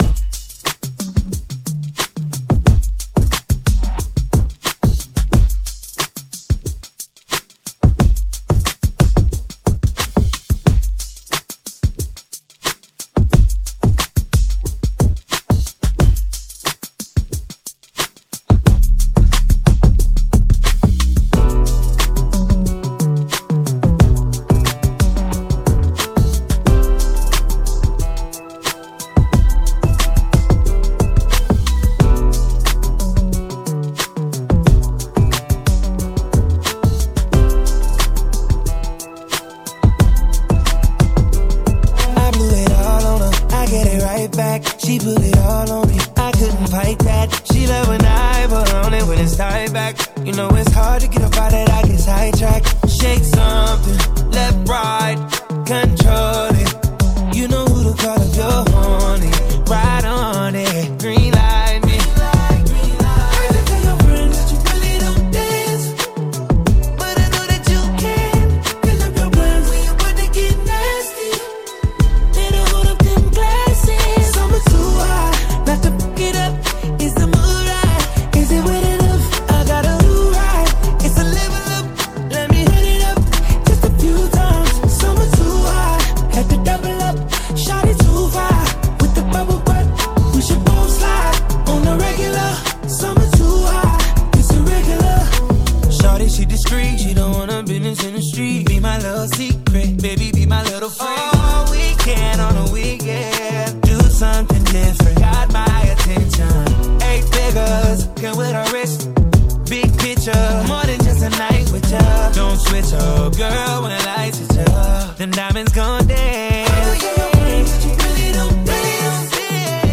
Genres: DANCE , TOP40 Version: Clean BPM: 124 Time